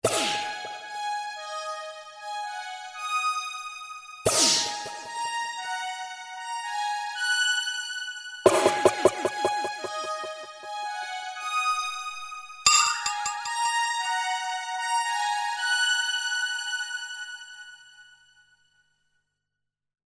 Synth + Percussions, 131 KB
tb_synth_percussions.mp3